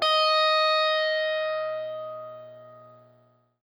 SPOOKY    AU.wav